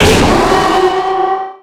Cri de Méga-Démolosse dans Pokémon X et Y.
Cri_0229_Méga_XY.ogg